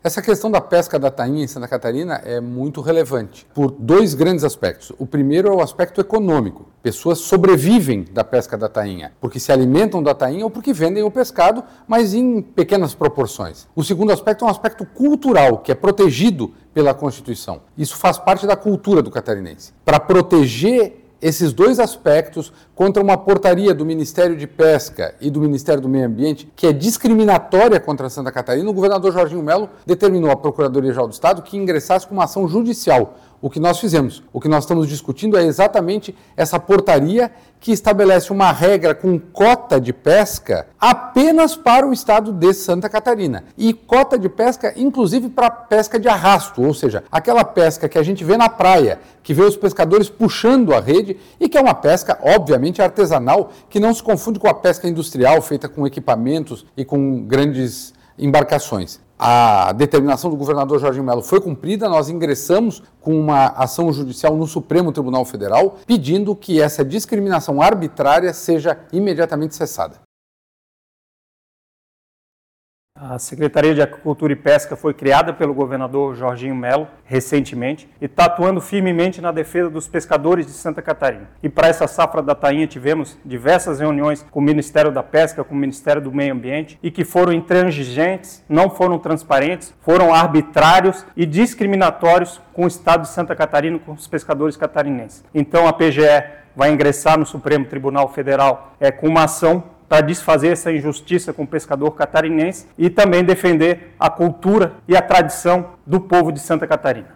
O procurador-geral do Estado, Márcio Vicari, explica que na ação, foram apontados além da inexistência de cotas para outros Estados, os fatores culturais e econômicos que a atividade representa para Santa Catarina, além da ausência de impacto ambiental:
O secretário da Aquicultura e Pesca de Santa Catarina, Tiago Bolan Frigo, disse que a pasta está atuando firmemente na defesa dos interesses dos pescadores artesanais de SC: